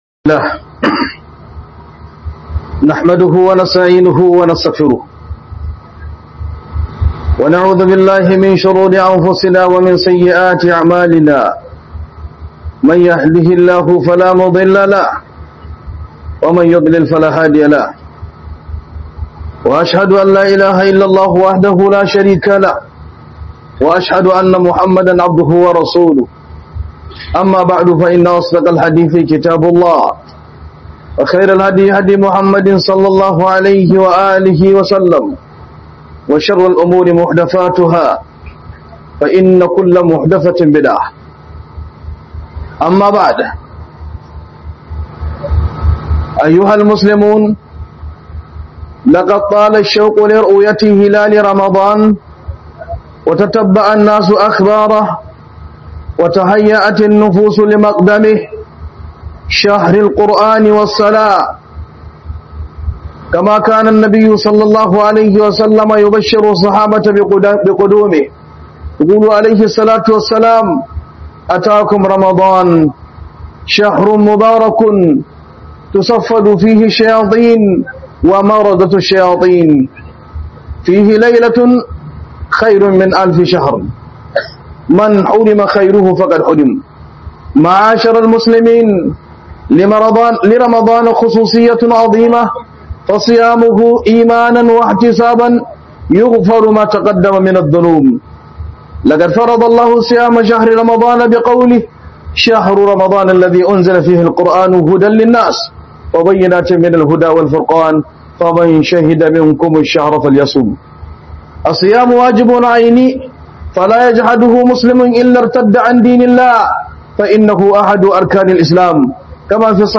Maraba Da Watan Ramadan - HUDUBA